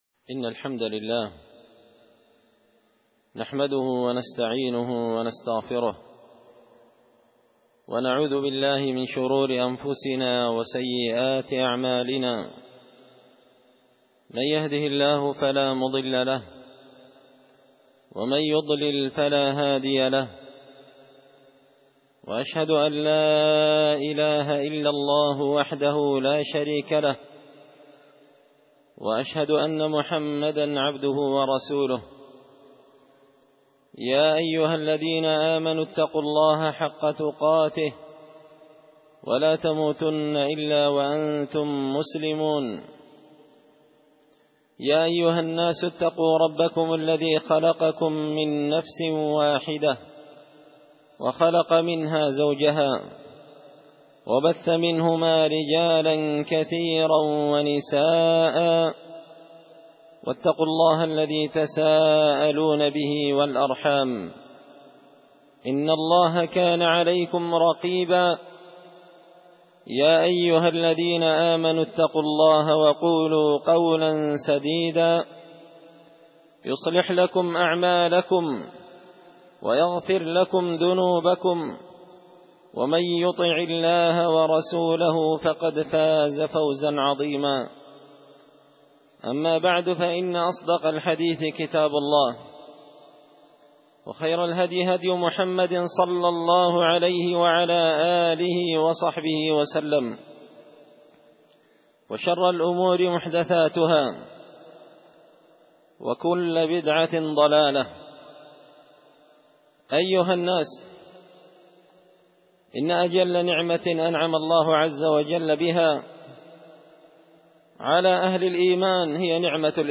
خطبة جمعة بعنوان – أنواع المواساة
دار الحديث بمسجد الفرقان ـ قشن ـ المهرة ـ اليمن